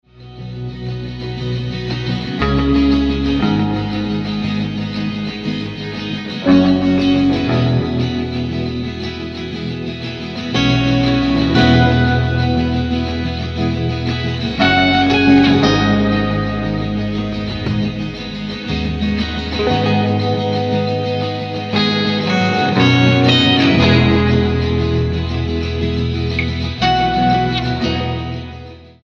STYLE: Rock
is suitably represented by jangly acoustic guitars